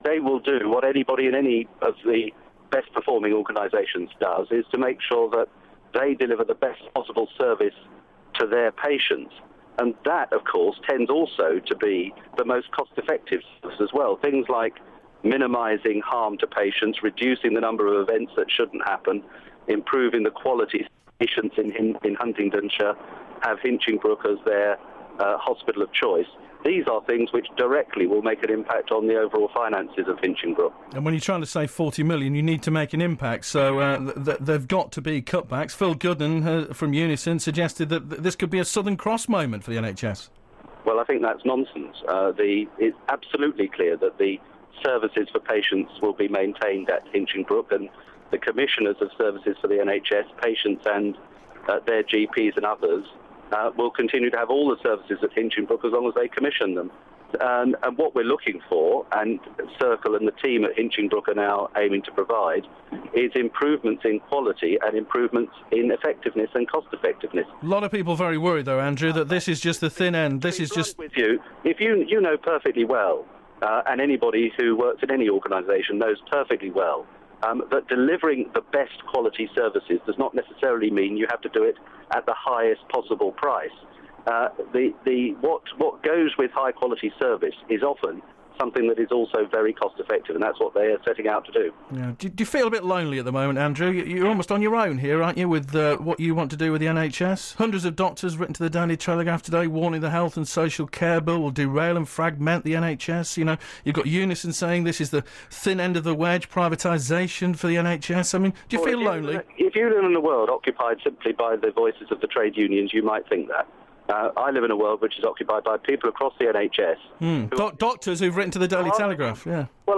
Interview with Andrew Lansley